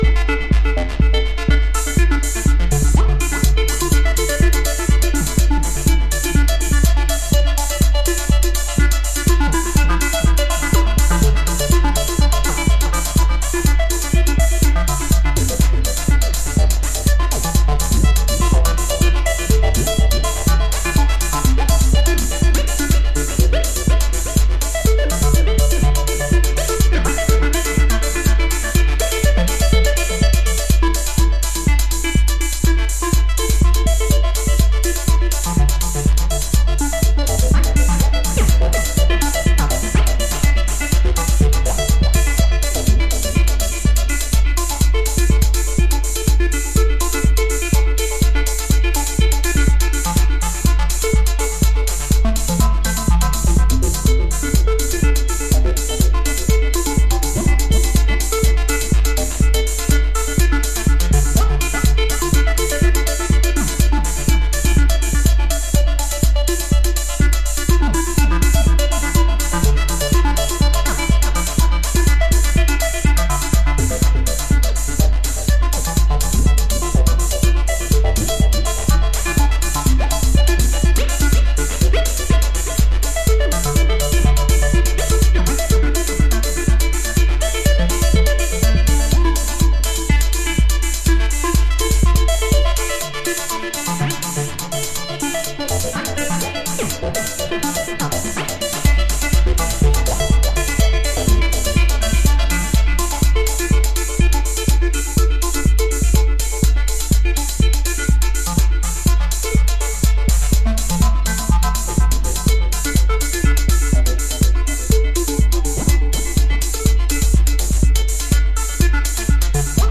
House / Techno
200series Plus 808